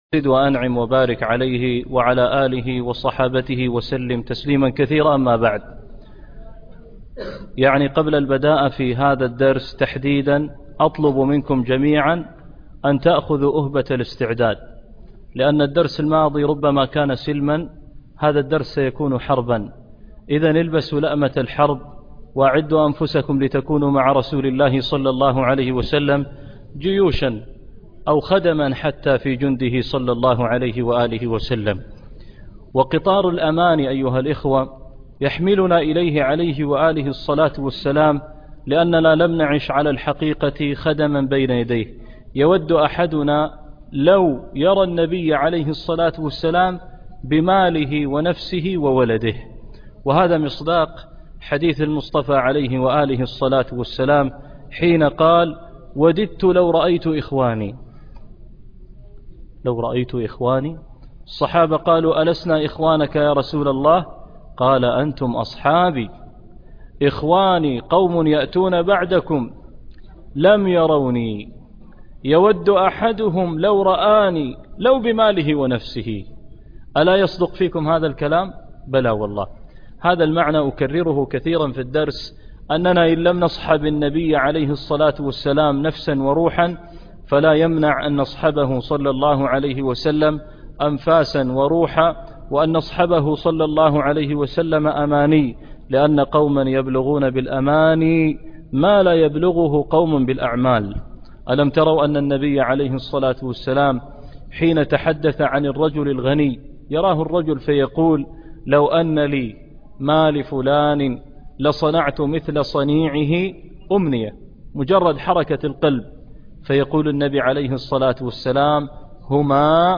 درس السيرة النبوية 30